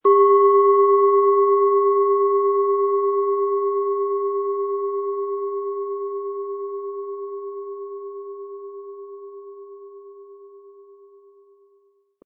Es ist eine von Hand getriebene Klangschale, aus einer traditionellen Manufaktur.
Im Lieferumfang enthalten ist ein Schlegel, der die Schale wohlklingend und harmonisch zum Klingen und Schwingen bringt.
SchalenformBihar
MaterialBronze